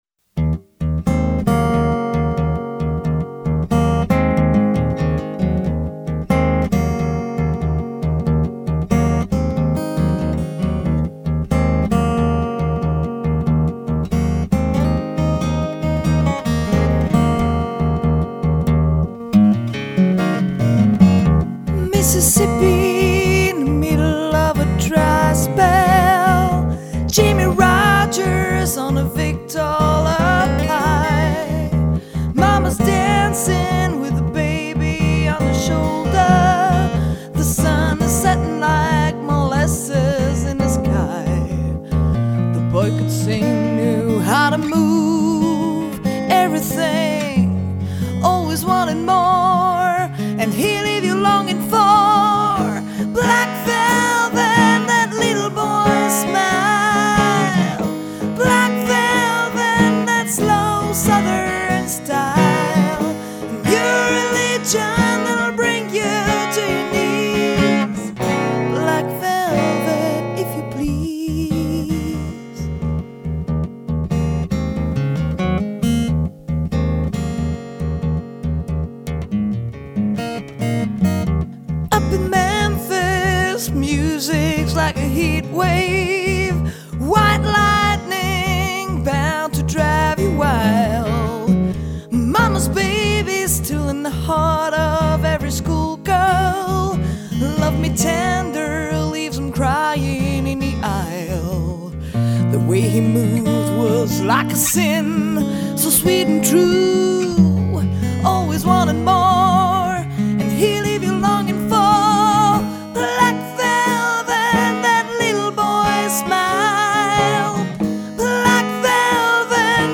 Chanteuse, animatrice, DJ